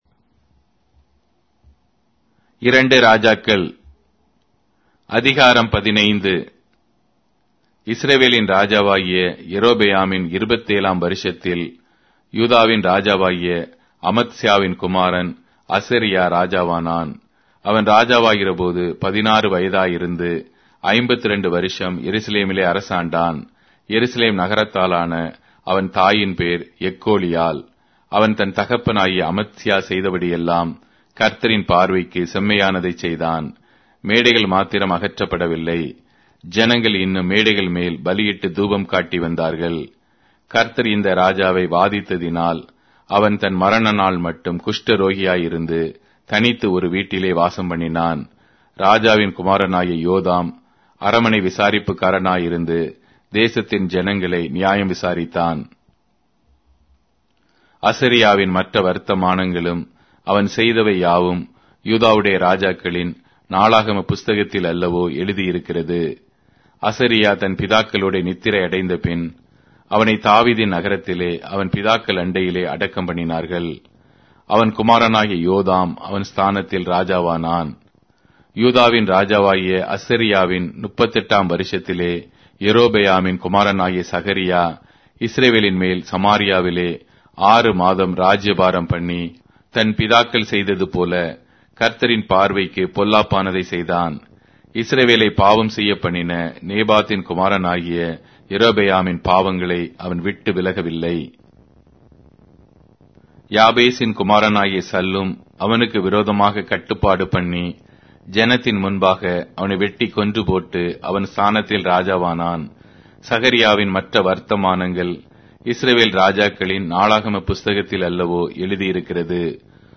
Tamil Audio Bible - 2-Kings 1 in Web bible version